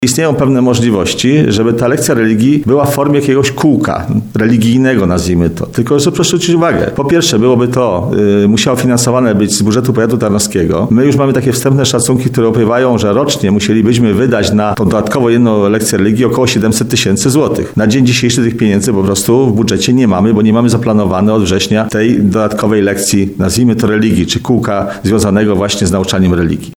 Po wstępnych analizach wicestarosta Roman Łucarz odpowiada, że na ten moment w budżecie nie ma środków, które mogą zostać przeznaczone na taki cel.